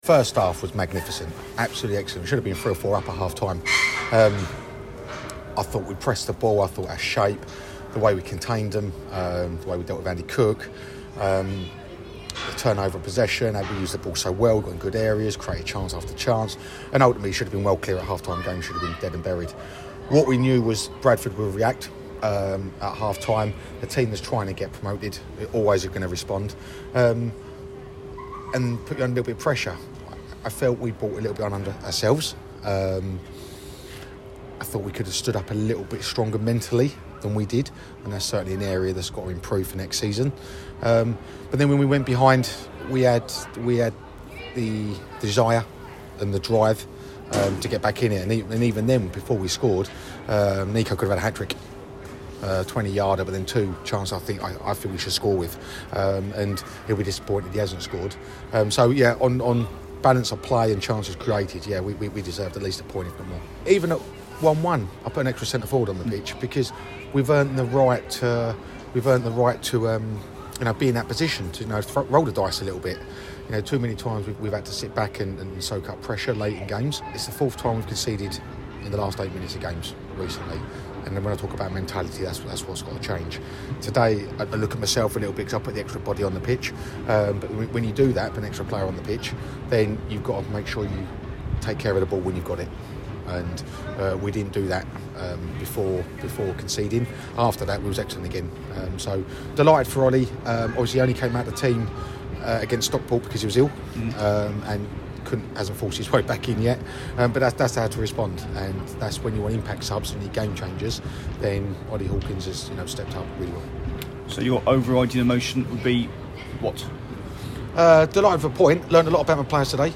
LISTEN: Gillingham manager Neil Harris spoke to us after their 2-2 draw at Bradford City - 23/04/2023